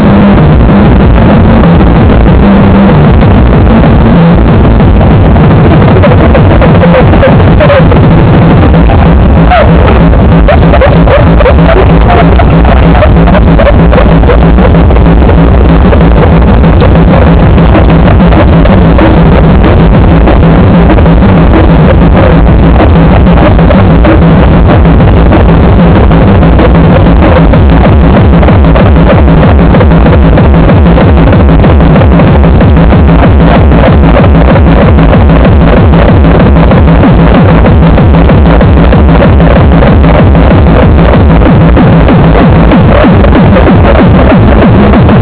Le teknival c’est un grand rassemblement de musique électronique, dégustation de plats épicés, boissons enivrantes et plus si affinités. Cette musique envahi le corps à en faire perdre le rythme cardiaque, une danse individuelle à faire oublier les 40000 personnes autour.